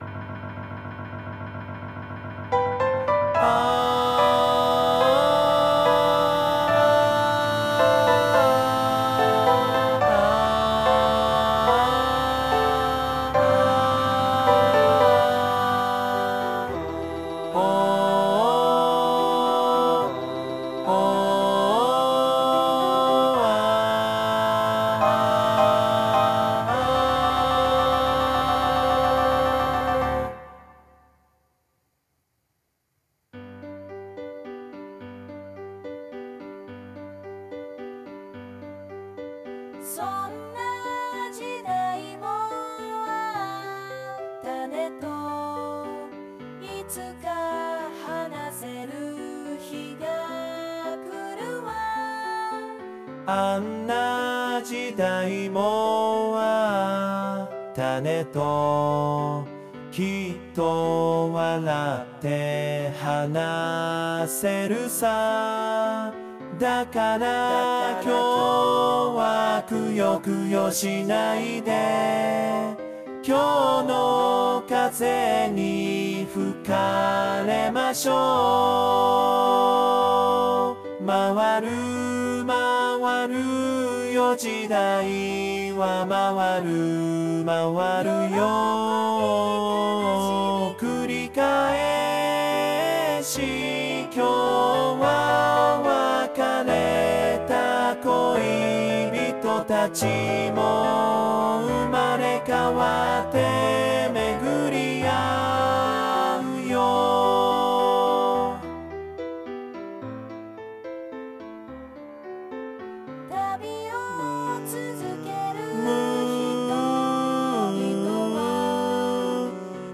「時代」音取り音源